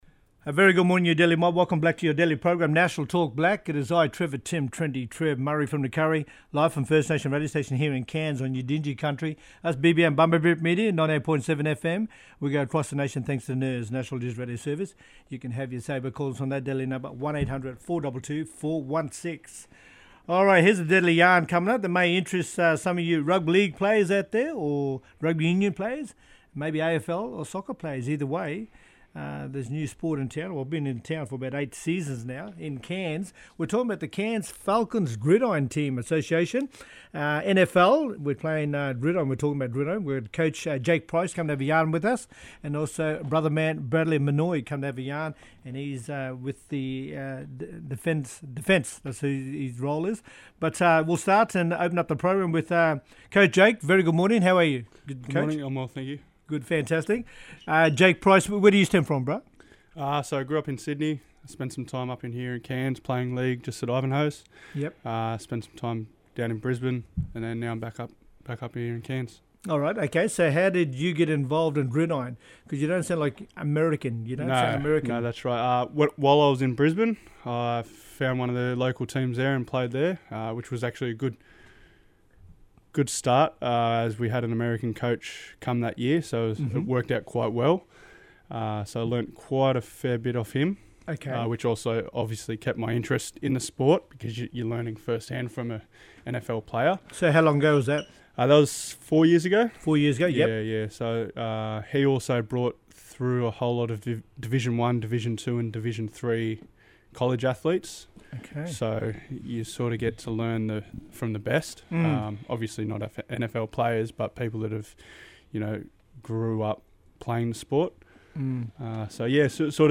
Cairns Gridiron Association Falcons Interview